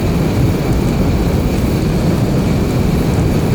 flamethrower-mid-2.ogg